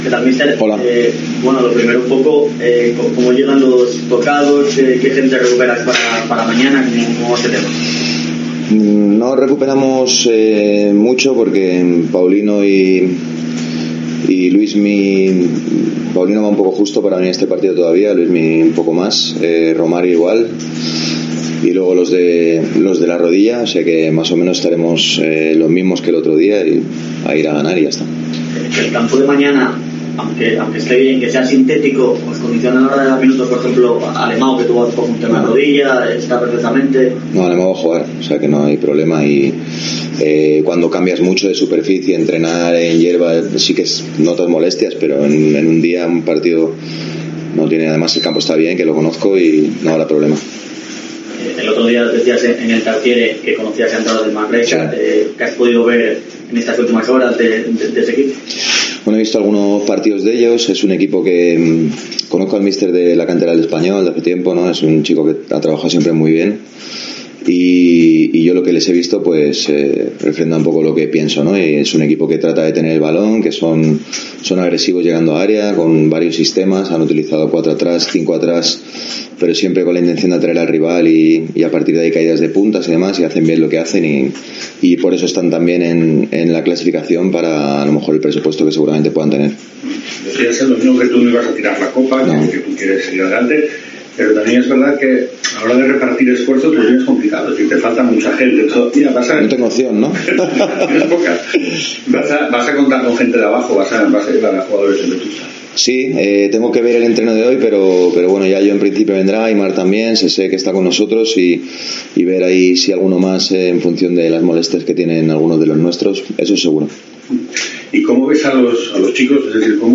Última sesión en El Requexón y rueda de prensa de Luis Carrión